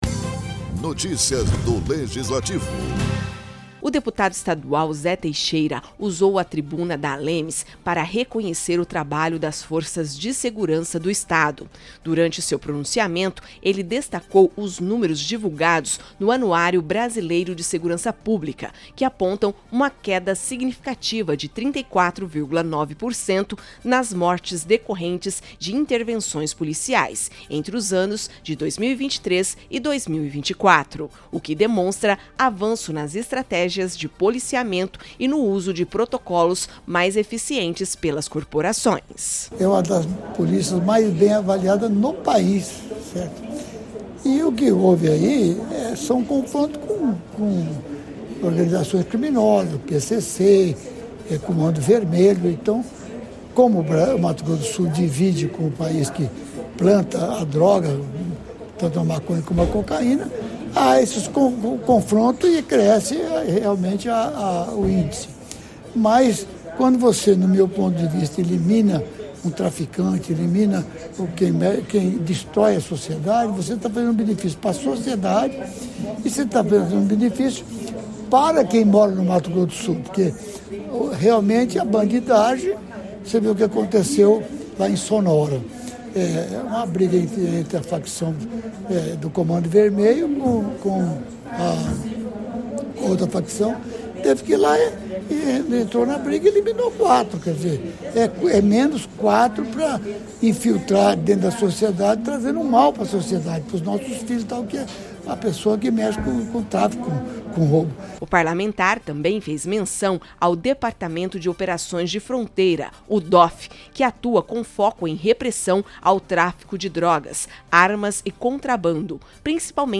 Durante pronunciamento na Assembleia Legislativa de Mato Grosso do Sul (ALEMS), o deputado Zé Teixeira enalteceu os resultados positivos da segurança pública em Mato Grosso do Sul. Ele destacou a redução de 34,9% nas mortes por intervenção policial, segundo o Anuário Brasileiro de Segurança Pública, além do trabalho estratégico do DOF e dos investimentos estaduais em tecnologia, infraestrutura e valorização profissional.